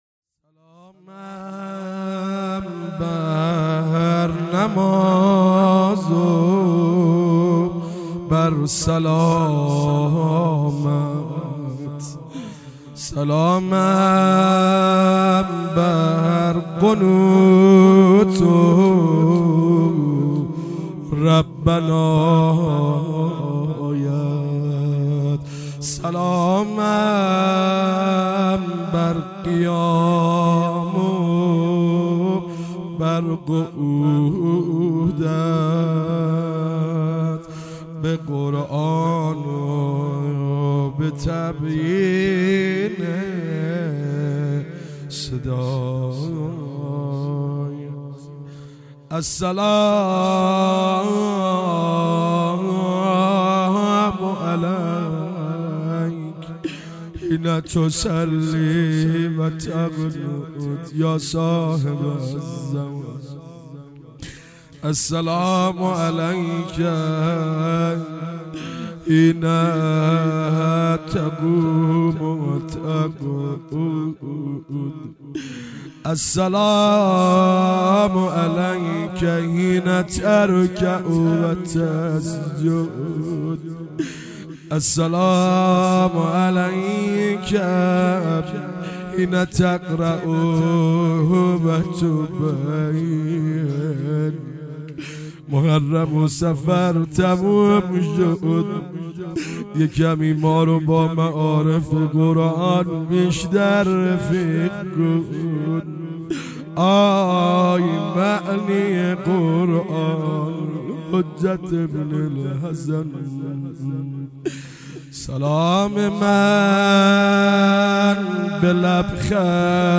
روضه - سلامم بر نماز و بر سلامت